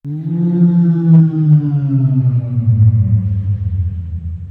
ghost_dies.ogg